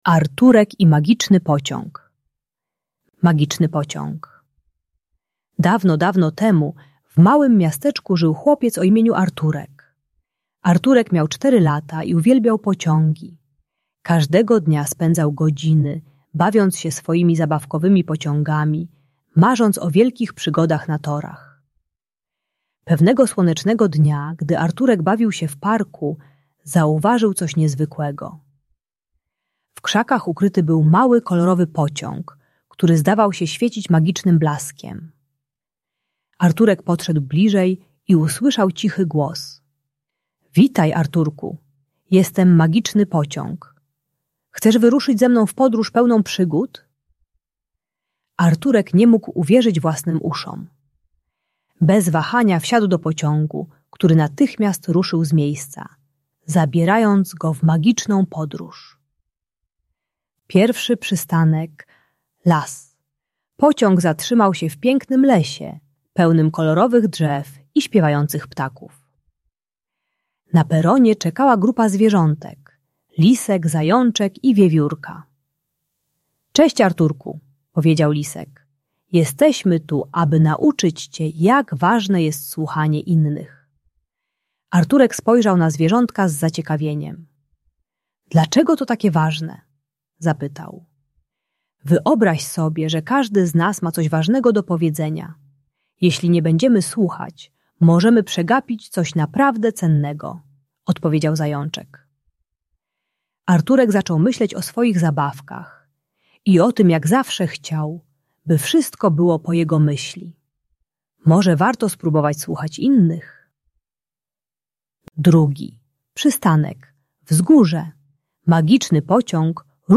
Magiczny Pociąg - Niepokojące zachowania | Audiobajka